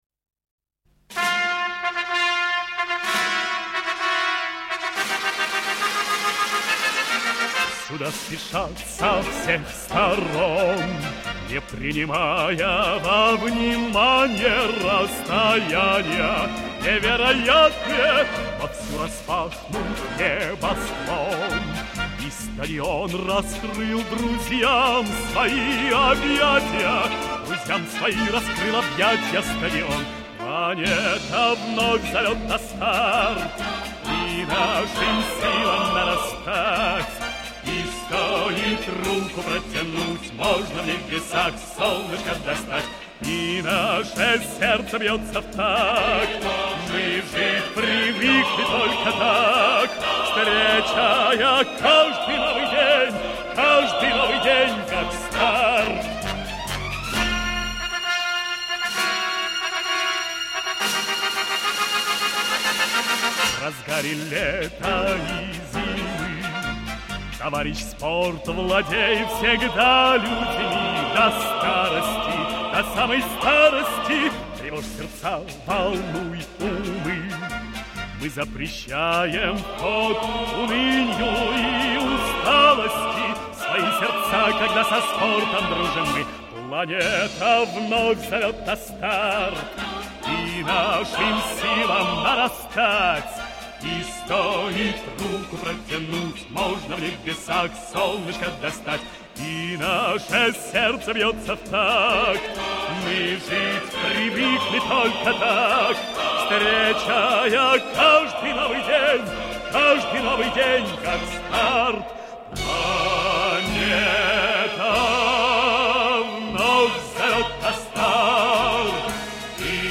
Возможно, я ошибаюсь, но эта  мелодия напоминает цирковую
Это инструментальная версия